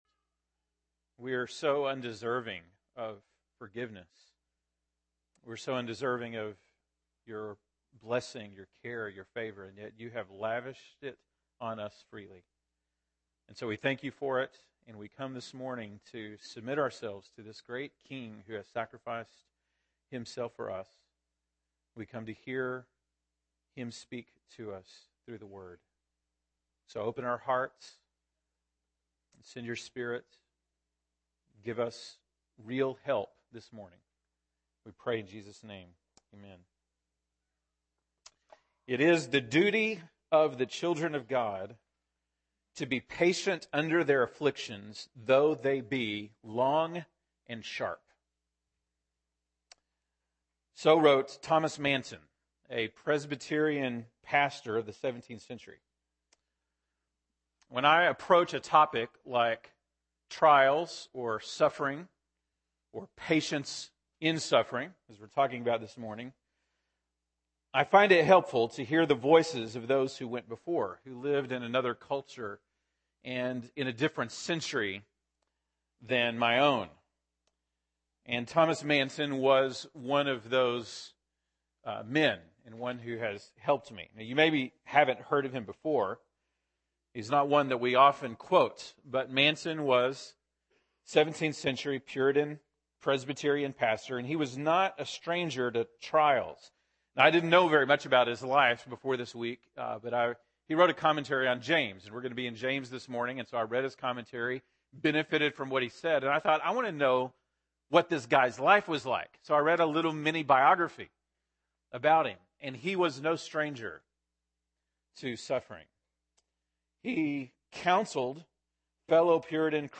February 22, 2015 (Sunday Morning)